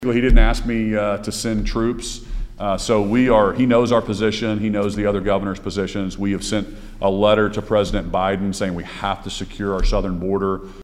CLICK HERE to listen to details from Governor Kevin Stitt.